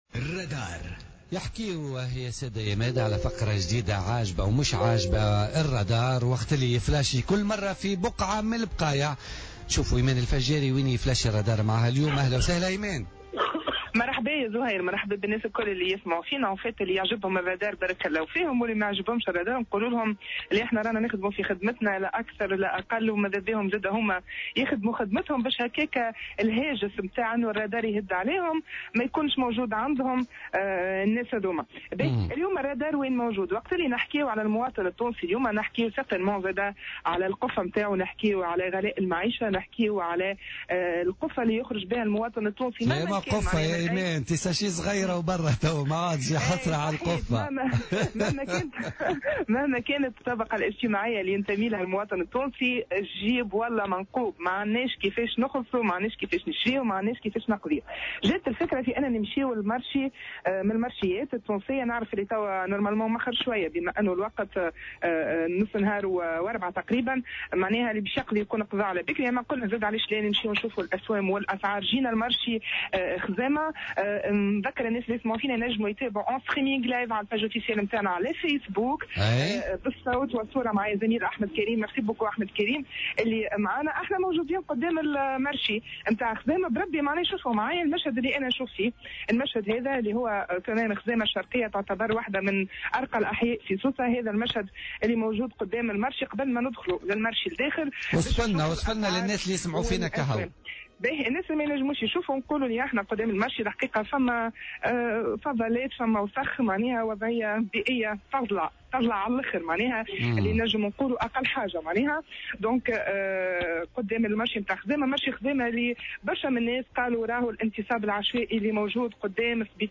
تحول فريق فقرة "الرادار" اليوم الاربعاء إلى سوق الخضر والغلال بمنطقة خزامة، حيث عاين ارتفاع الاسعار و تراكم الفضلات وغلق بعض المحلات لأبوابها.